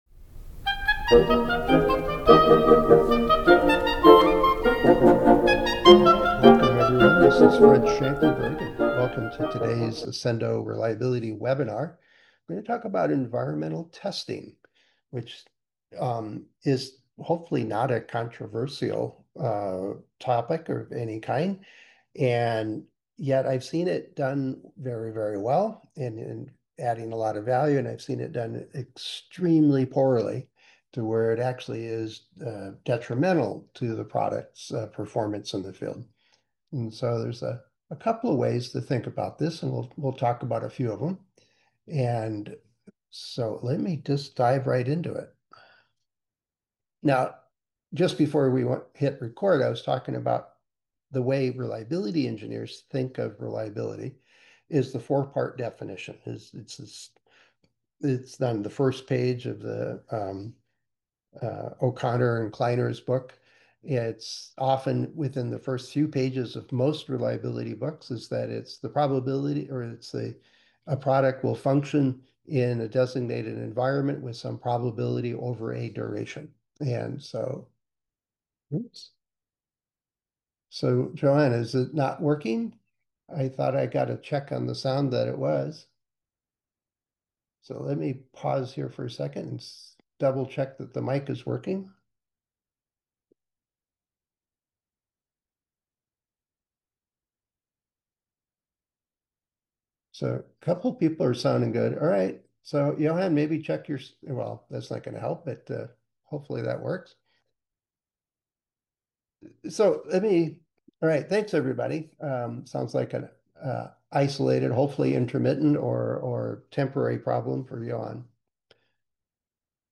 Episode from Accendo Reliability Webinar Series